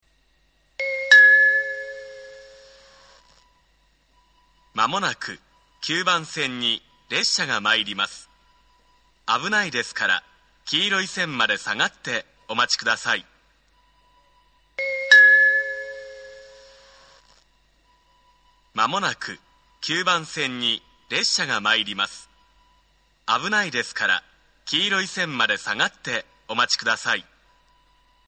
仙石線ホームは地下にあり、通常のROMベルを使用しています。
９番線接近放送